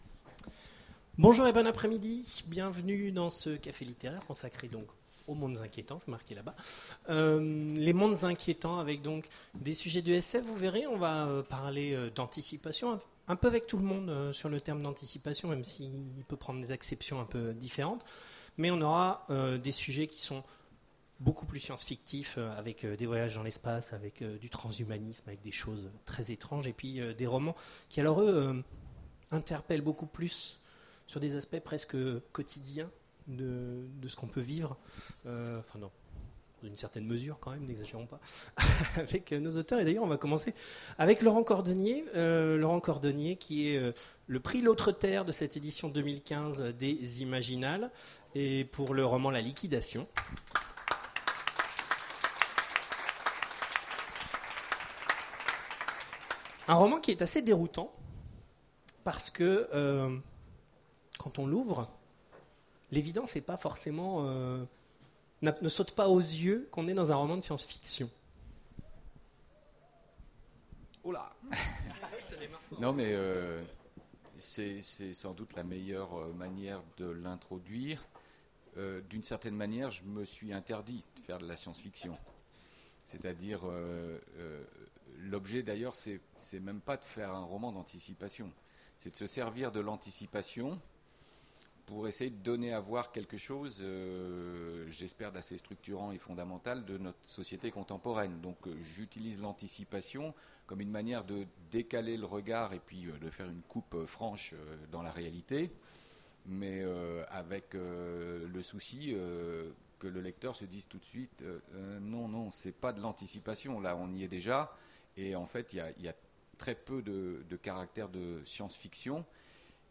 Imaginales 2015 : Conférence Mondes inquiétants...